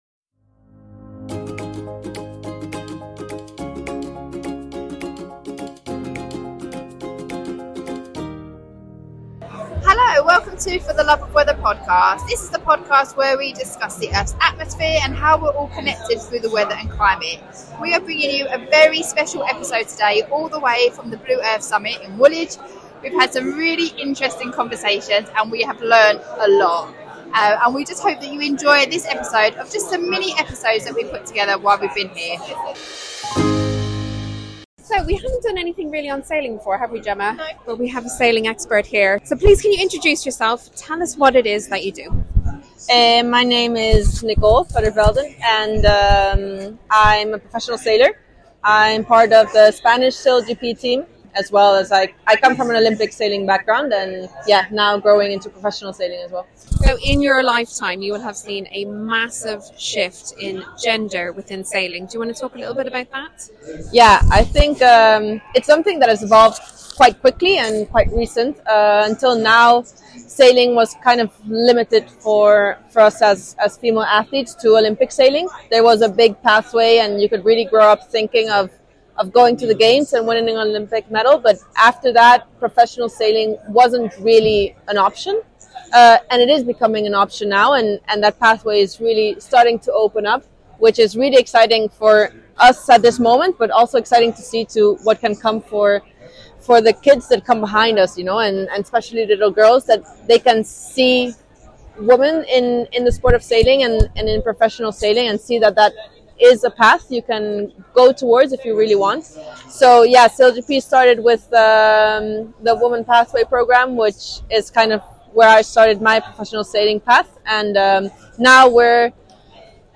We were thrilled to return to the Blue Earth Summit 2024 this time in London.
We captured and shared as many speakers and guests as we could and yet again we were blown away by the amazing conversations we had and most importantly people’s willingness to give us their time to talk about what they were passionate about.